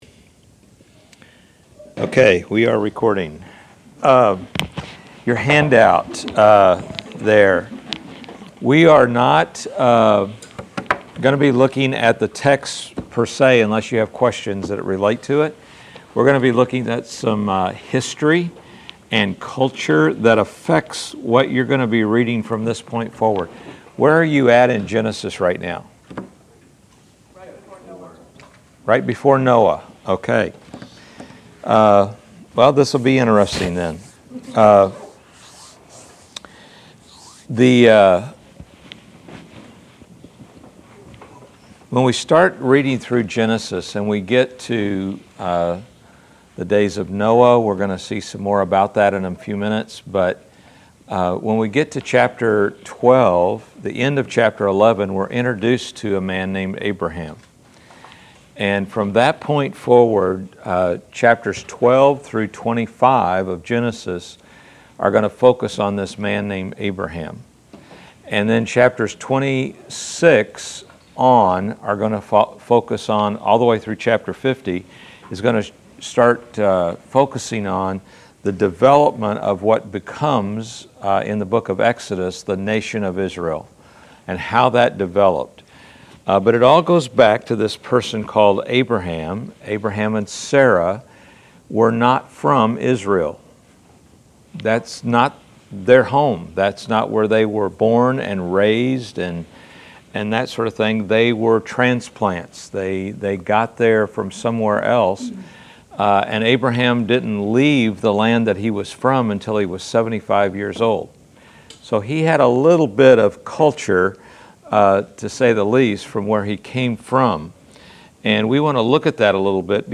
Class Session Audio September 26